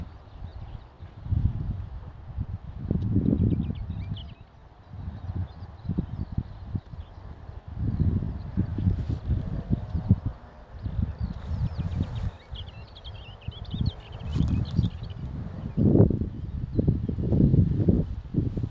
Klusais ķauķis, Iduna caligata
StatussDzied ligzdošanai piemērotā biotopā (D)